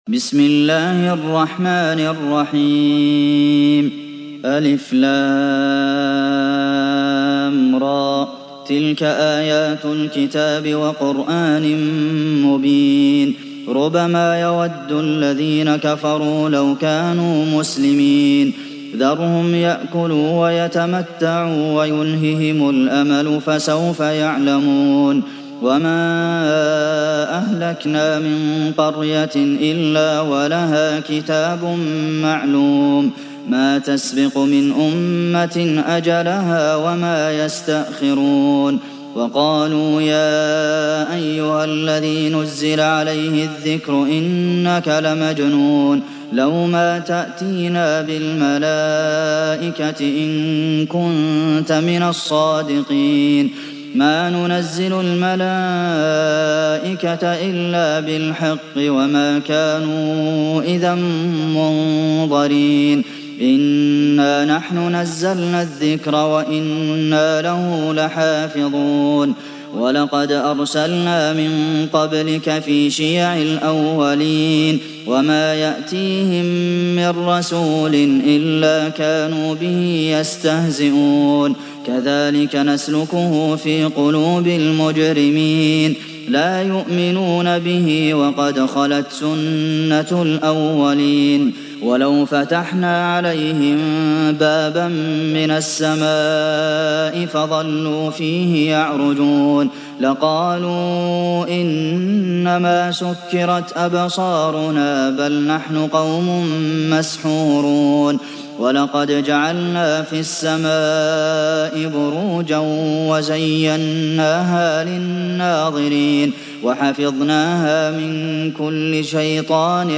Sourate Al Hijr Télécharger mp3 Abdulmohsen Al Qasim Riwayat Hafs an Assim, Téléchargez le Coran et écoutez les liens directs complets mp3